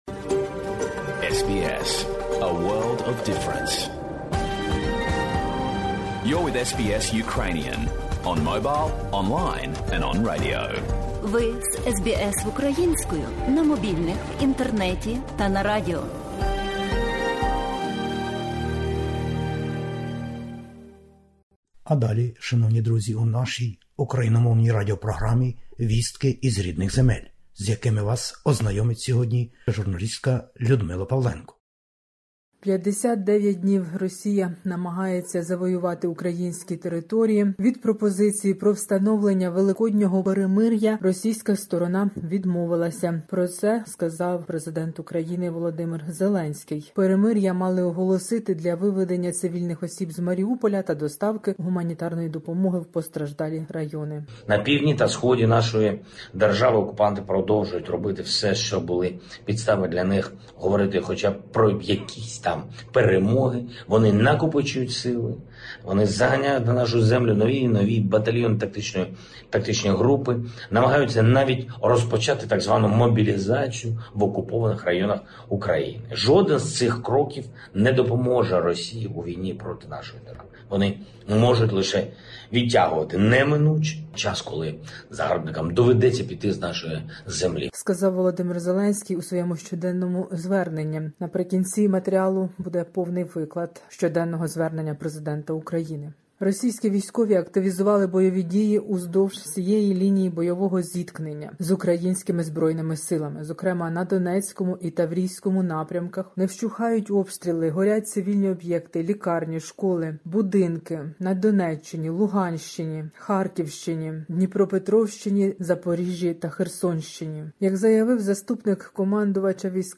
Добірка новин із воюючої України спеціяльно для SBS Ukrainian. Війна в Україні: Росія відмовилася від великоднього перемиря, запропопнованого Україною та міжнародним співтовариством. Звернення Президента України.